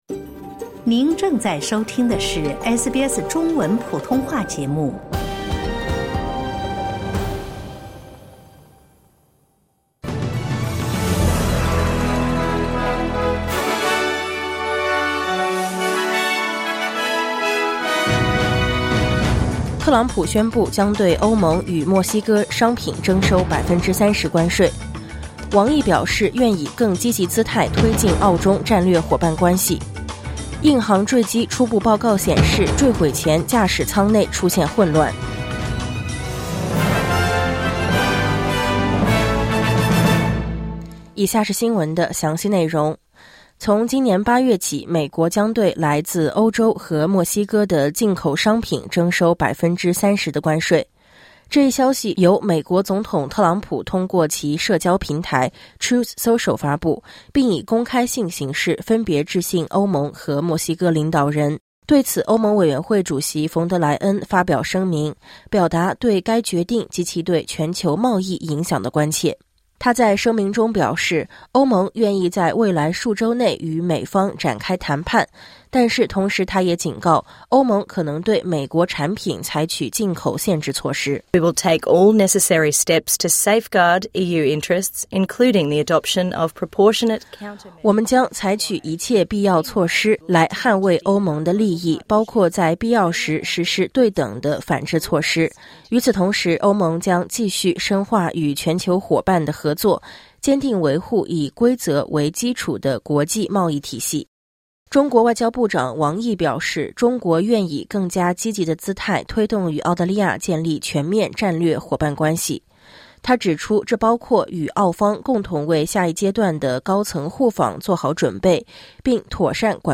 SBS早新闻（2025年7月13日）
SBS Mandarin morning news Source: Getty / Getty Images